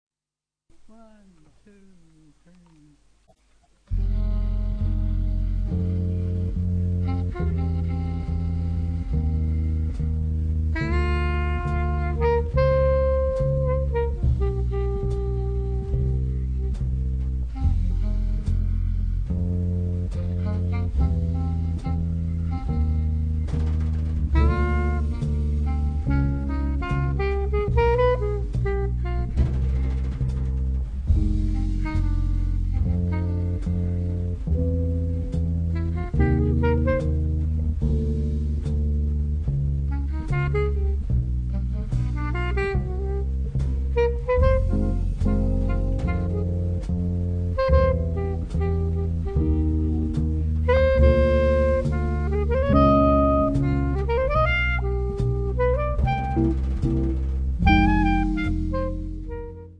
Live in Poggio alla Croce (Fi), 23 luglio 2005
clarinetto
chitarra
contrabbasso
batteria